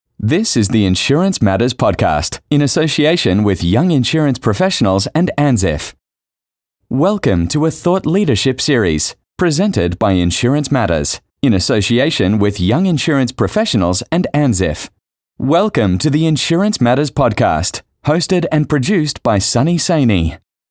Never any Artificial Voices used, unlike other sites.
Podcasting Voice Over Talent
Yng Adult (18-29) | Adult (30-50)